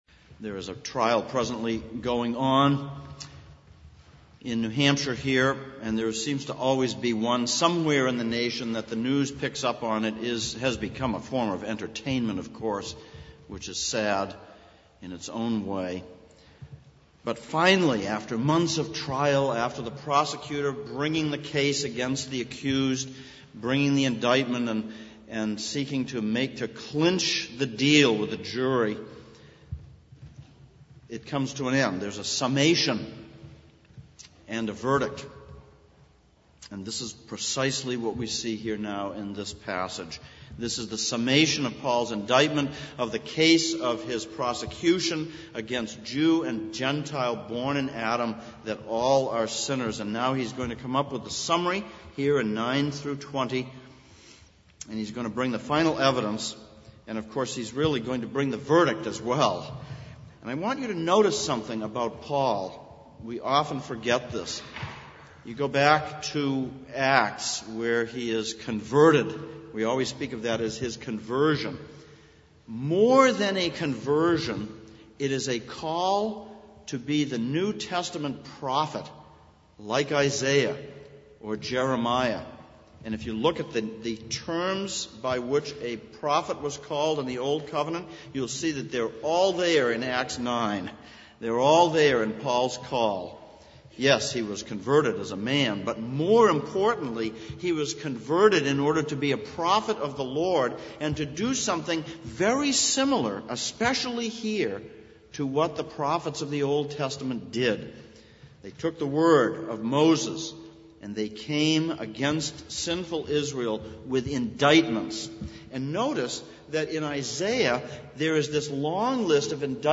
Exposition of Romans Passage: Romans 3:9-20 Service Type: Sunday Morning « 11.